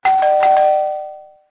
doorbl0f.mp3